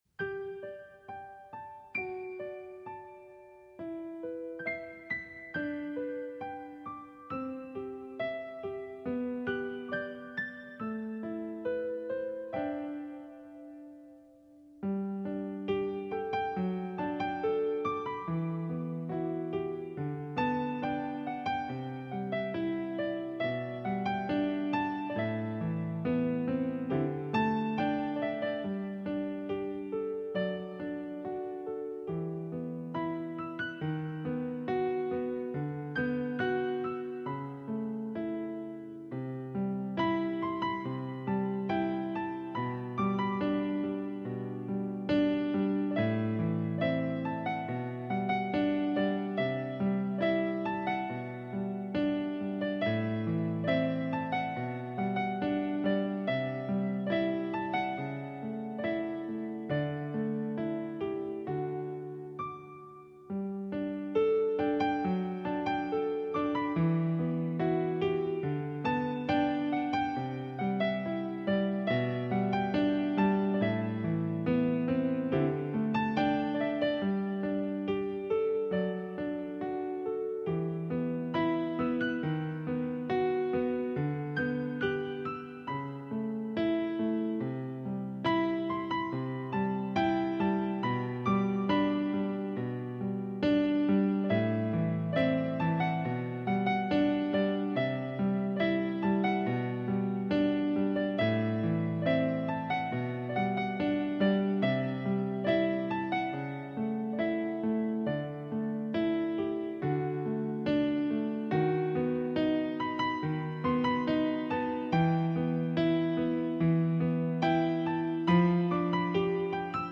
A4tech FM10 Optical Wired Mouse sound effects free download